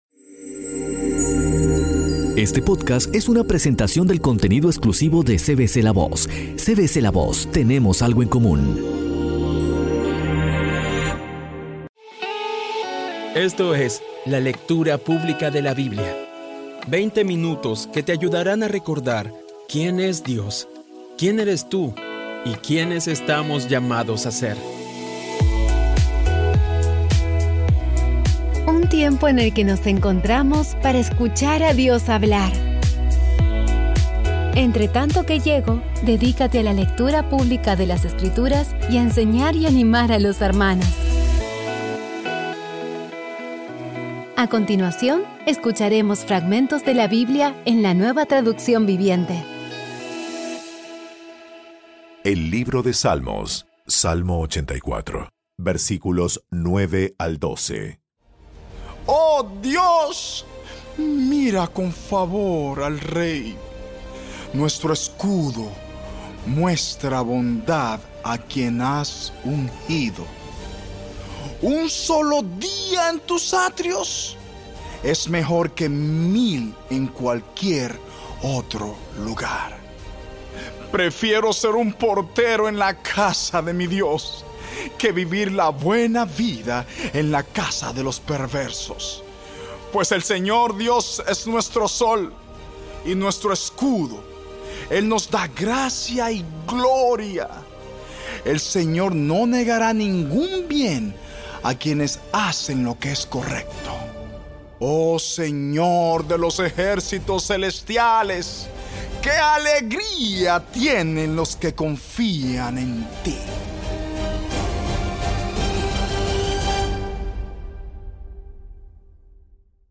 Audio Biblia Dramatizada Episodio 204
Poco a poco y con las maravillosas voces actuadas de los protagonistas vas degustando las palabras de esa guía que Dios nos dio.